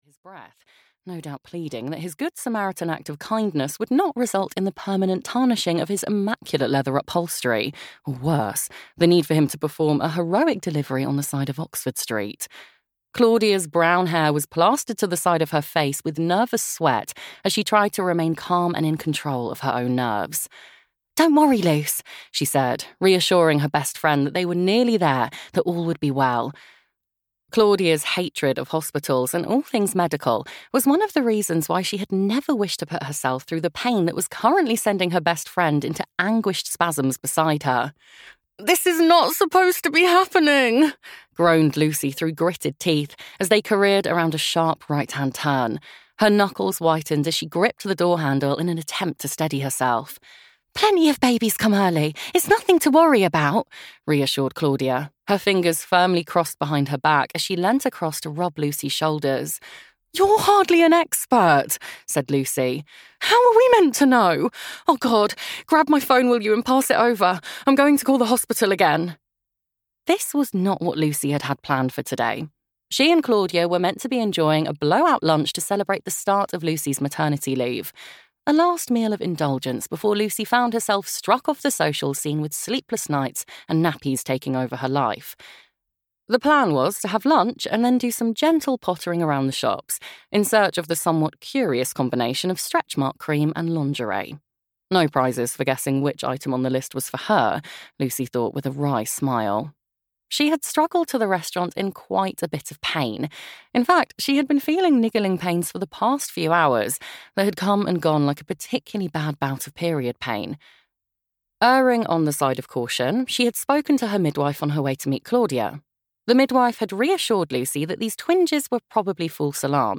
Just the Two of Us (EN) audiokniha
Ukázka z knihy